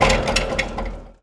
metal_gate_fence_impact_03.wav